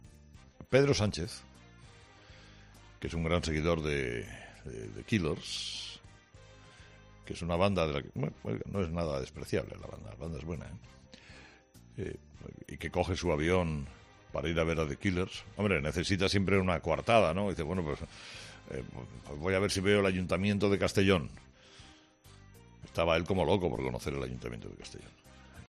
"Es muy típico, llegar a la presidencia y coger un avión para ir a un concierto. Muy de nuevo rico", ha concluido.